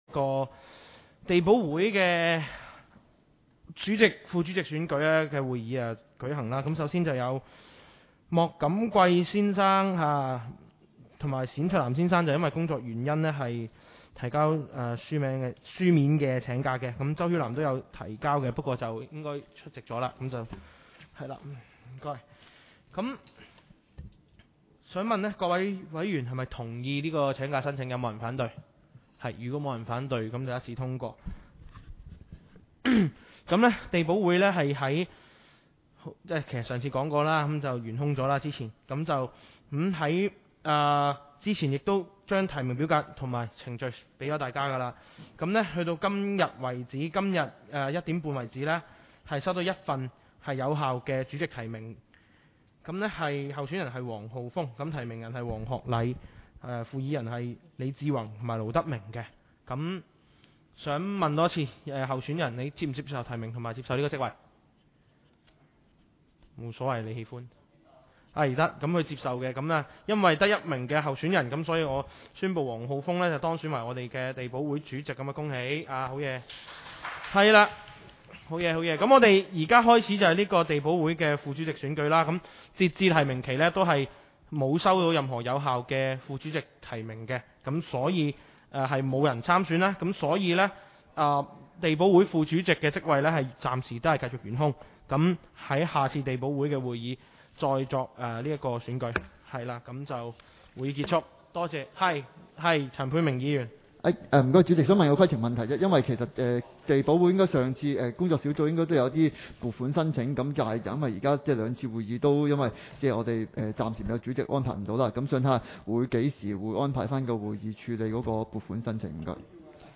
委员会会议的录音记录
地区设施管理及保安事务委员会第三次(续会)会议 日期: 2021-09-03 (星期五) 时间: 下午2时30分 地点: 沙田民政事务处 441 会议室 议程 讨论时间 I 选举委员会主席及副主席 00:03:05 全部展开 全部收回 议程:I 选举委员会主席及副主席 讨论时间: 00:03:05 前一页 返回页首 如欲参阅以上文件所载档案较大的附件或受版权保护的附件，请向 区议会秘书处 或有关版权持有人（按情况）查询。